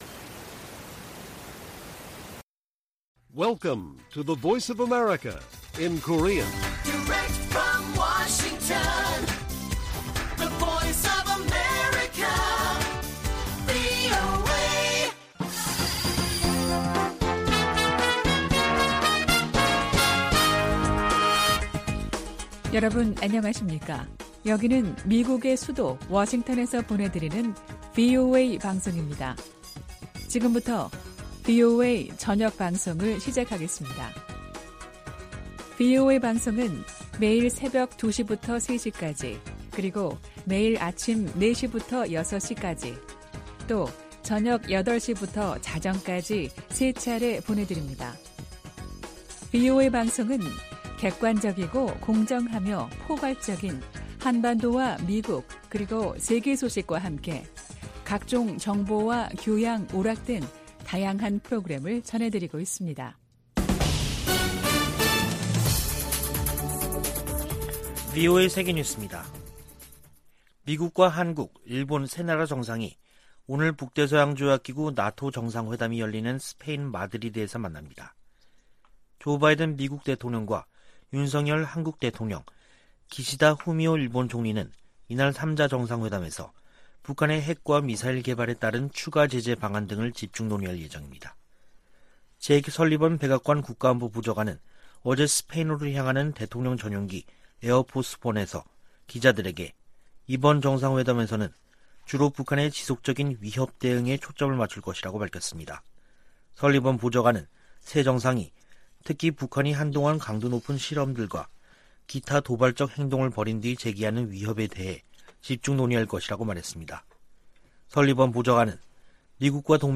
VOA 한국어 간판 뉴스 프로그램 '뉴스 투데이', 2022년 6월 29일 1부 방송입니다. 제이크 설리번 백악관 국가안보보좌관은 나토 정상회의 현장에서 열리는 미한일 정상회담에서 대북 경제 압박 방안이 논의될 것이라고 말했습니다. 옌스 스톨텐베르그 나토 사무총장은 새 전략개념을 제시하면서 중국과 러시아의 도전을 견제하겠다는 의지를 확인했습니다. 한국은 국제 규범과 법치, 인권 문제 등에서 나토에 기여할 수 있다고 미국 전직 관리들이 말했습니다.